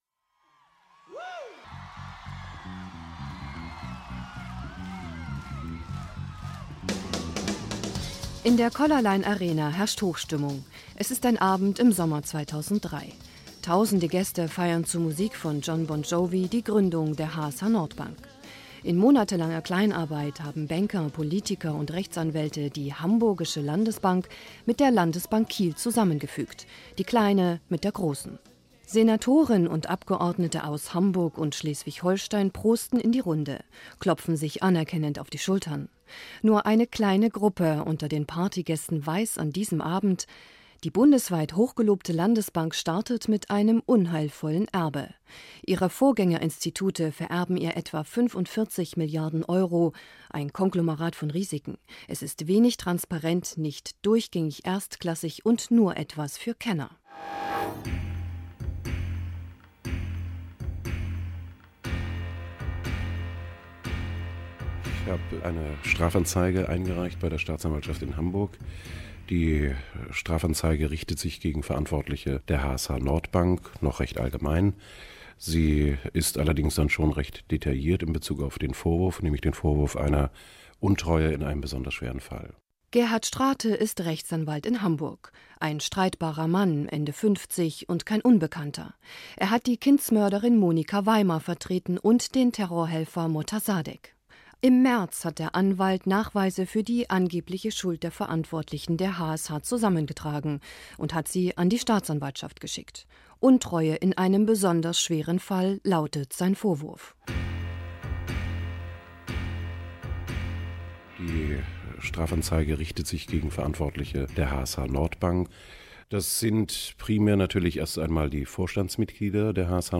Dieses Radio-Feature lief am 9. Juli 2009 auf NDR Info.